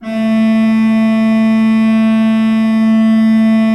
Index of /90_sSampleCDs/Propeller Island - Cathedral Organ/Partition G/HOLZGEDAKT M